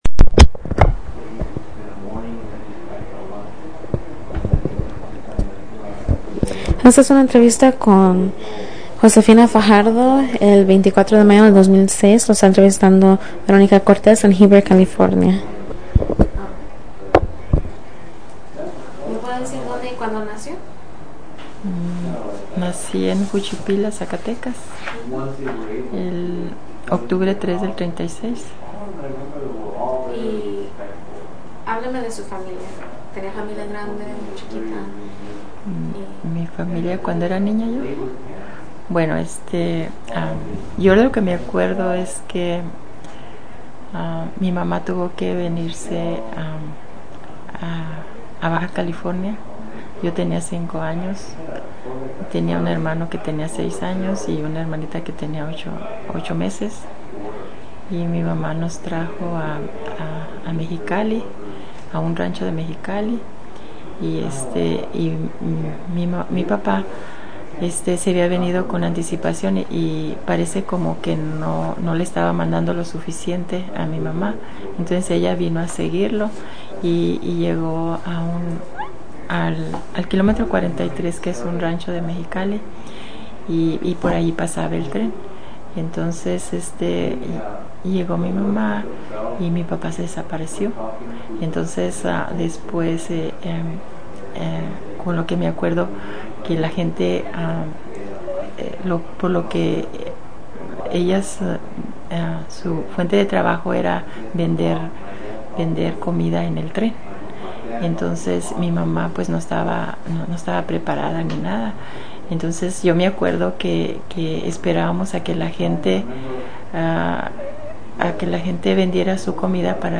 Summary of Interview: